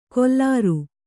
♪ kollāru